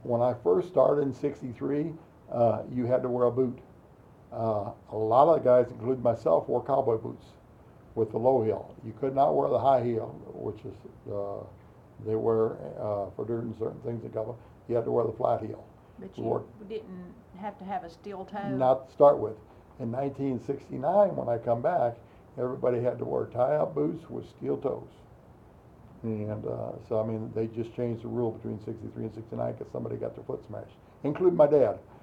Interview Clip